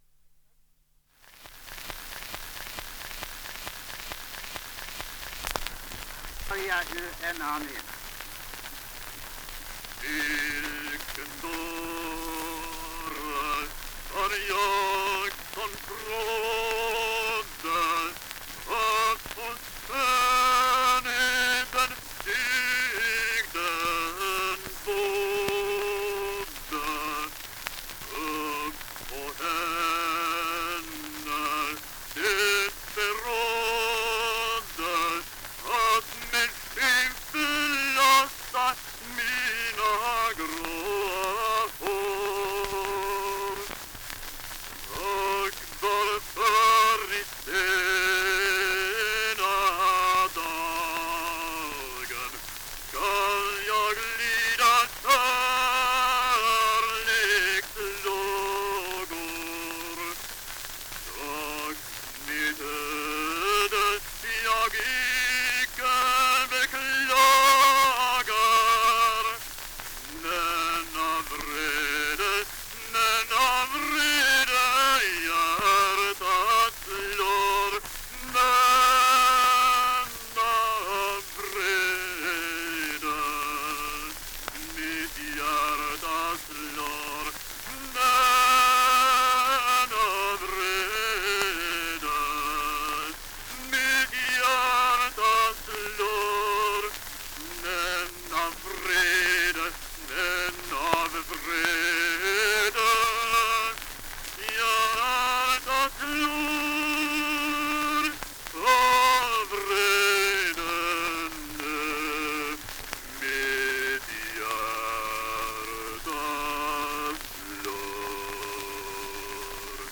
Aria ur Ernani